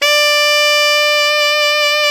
SAX ALTOFF0H.wav